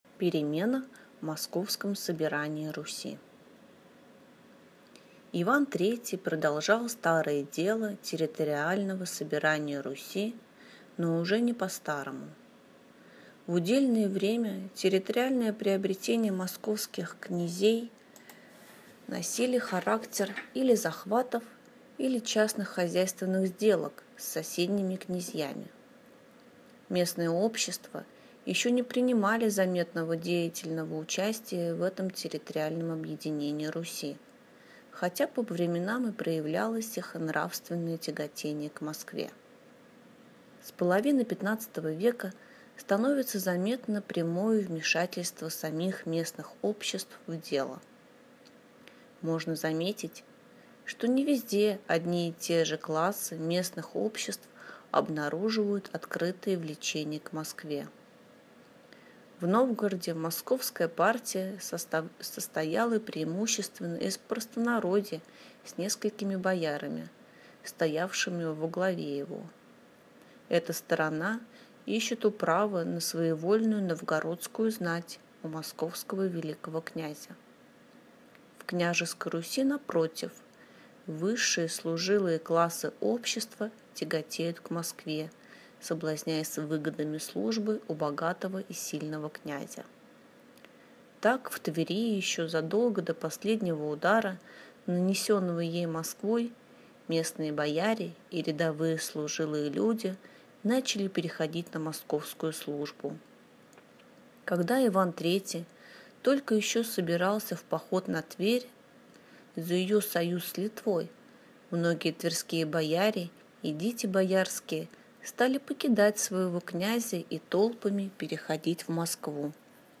Аудиокнига Иван III | Библиотека аудиокниг